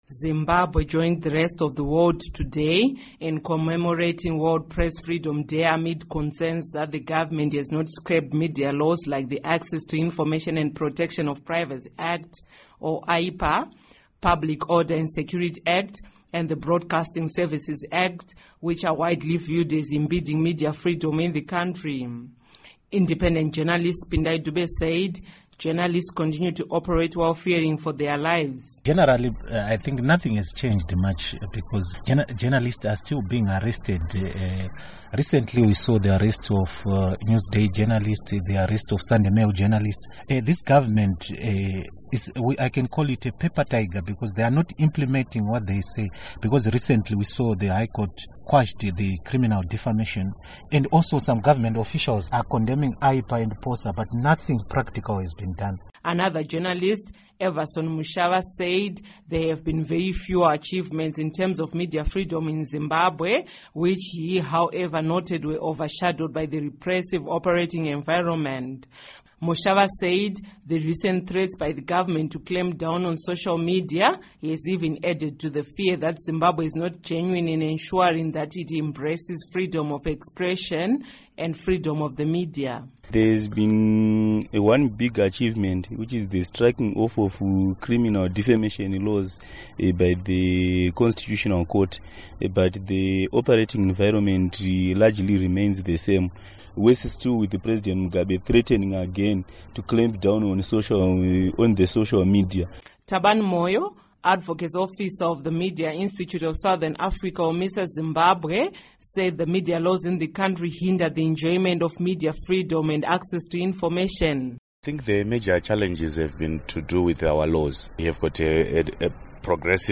Report on Press Freedom